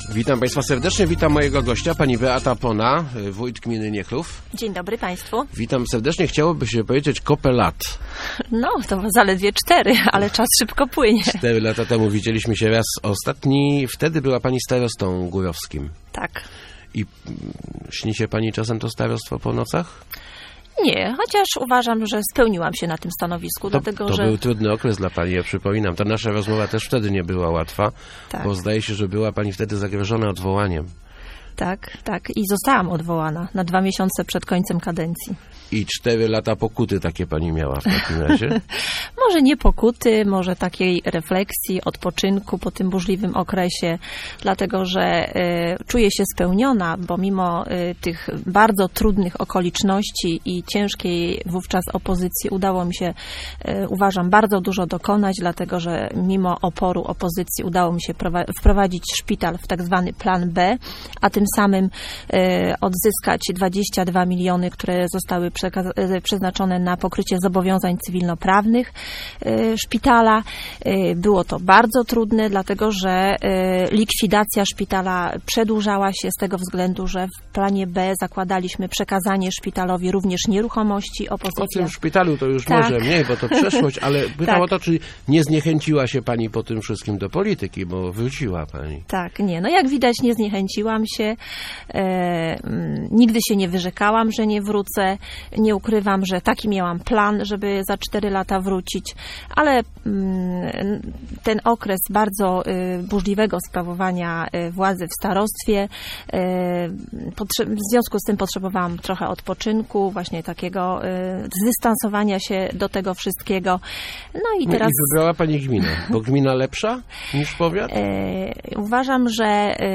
W przyszłym roku nie będzie jeszcze dużych inwestycji, ponieważ nie zostaną uruchomione środku unijne - mówiła w Rozmowach Elki Beata Pona, nowa wójt gminy Niechlów.